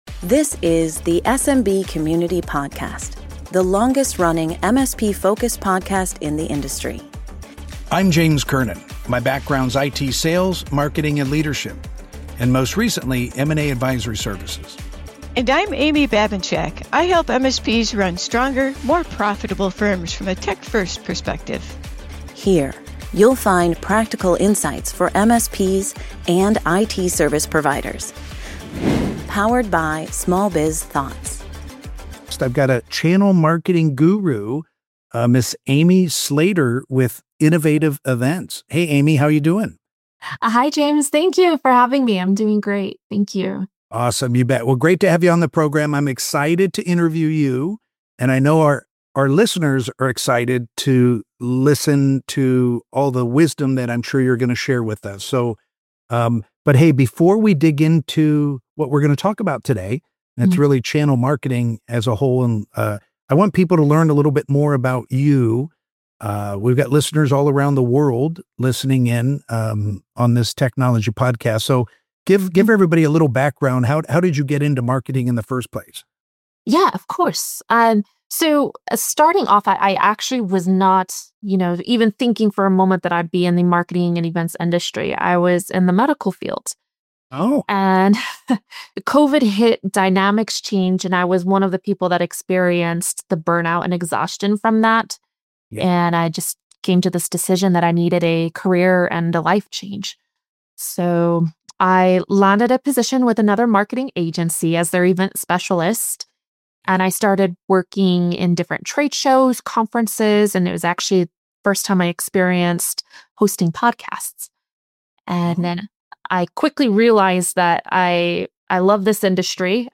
Exploring Channel Marketing: An Interview w